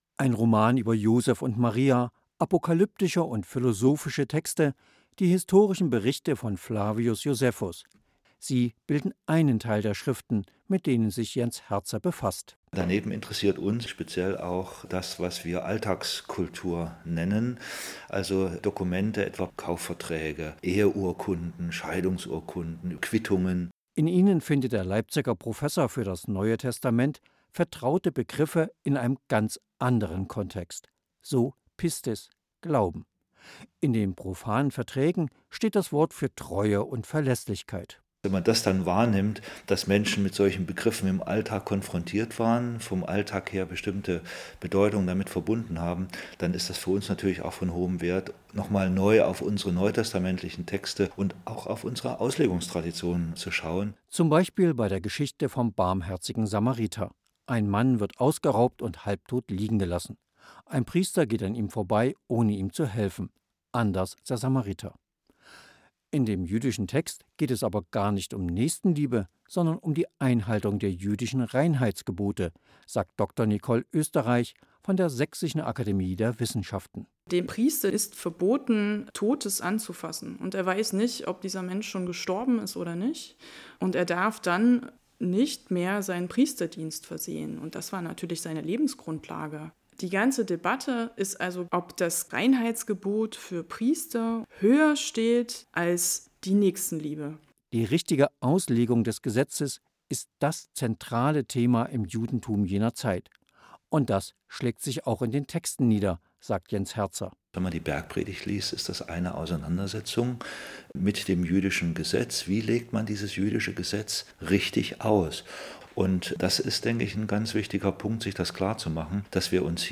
Radiointerview für MDR Kultur über unser Projekt
Radio; Interview; ProjektAktuelles